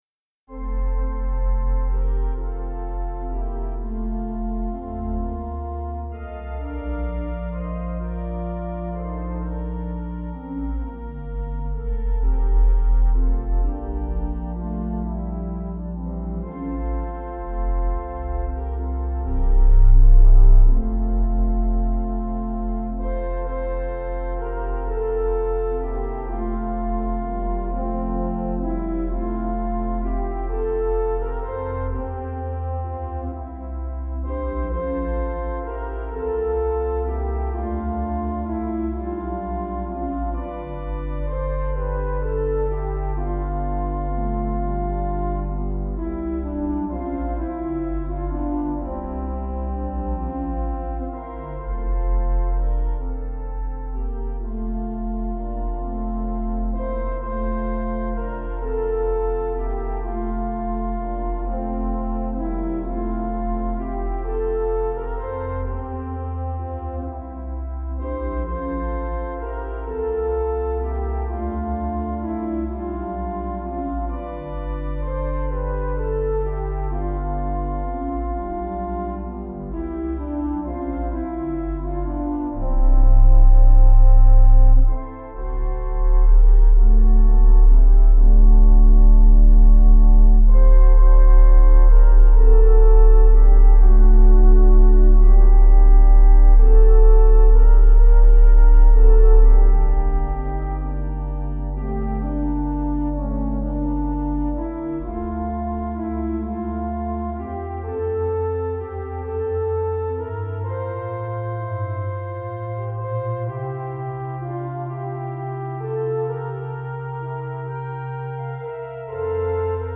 Easy Listening   F/Bb